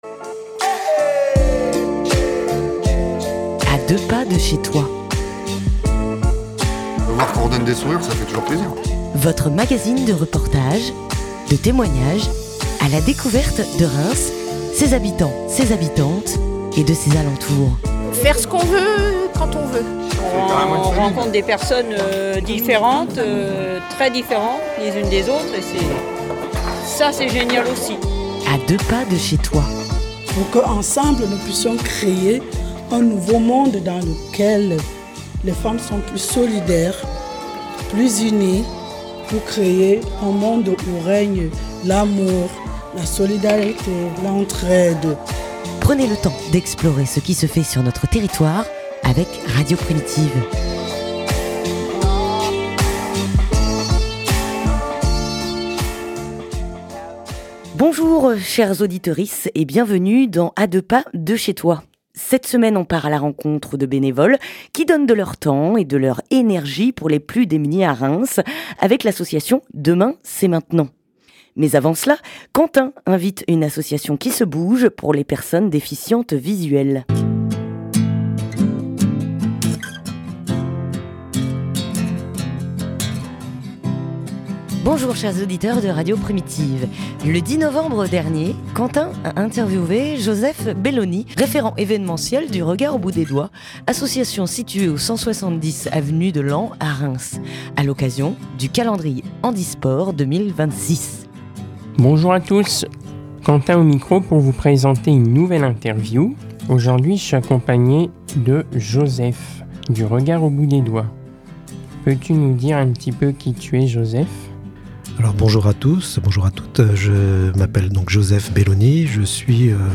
( de 00:00 à 18:00 ) - Radio Primitive s’est rendue au local de l’association Deux Mains c'est Maintenant pour rencontrer les bénévoles avant la distribution alimentaire.